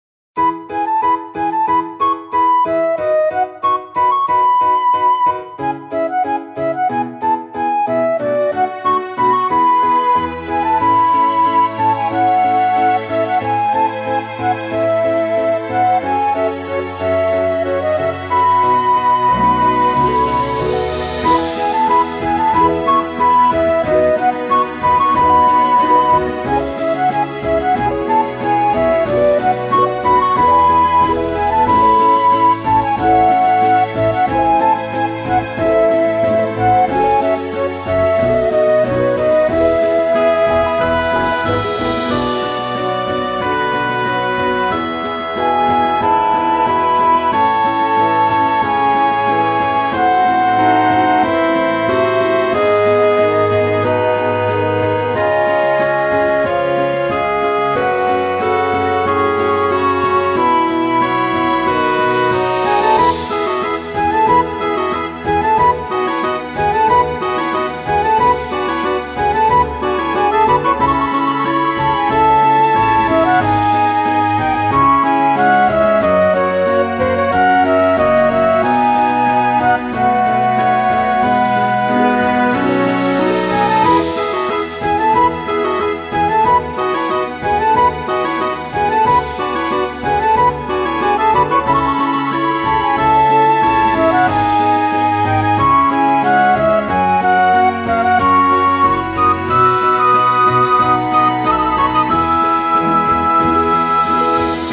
-oggをループ化-   希望 ｵｰｹｽﾄﾗ 2:16 mp3